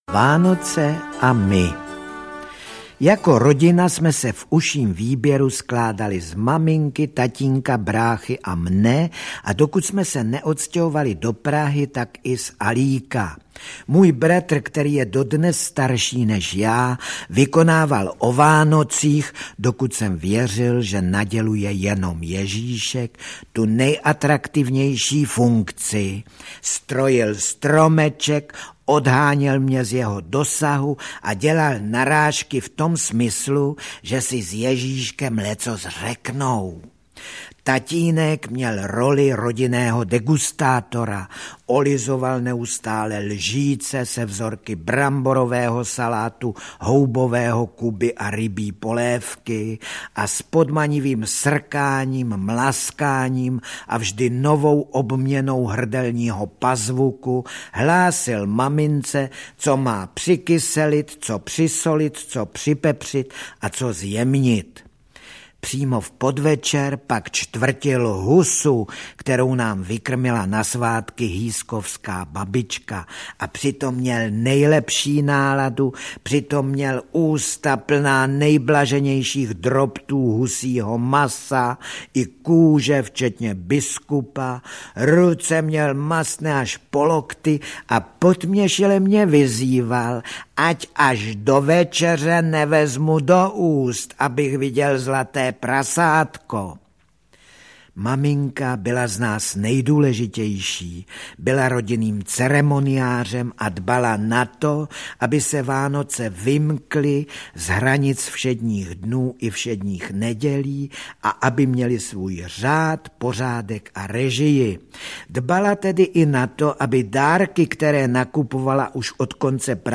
Audio kniha
Ukázka z knihy
Výběr připravil a dramaturgicky sestavil sám autor, a poté se samozřejmě osobně dostavil do studia a ujal se četby s neopakovatelným mistrovstvím prostého vypravěče, jehož slova hladí, dokáží vyloudit na tvářích posluchačů úsměv, ba dokonce vyprovokují i k hlasitému smíchu.
Vánoční atmosféru této nahrávky umocňují melodie známých koled a zvuk varhan.
• InterpretFrantišek Nepil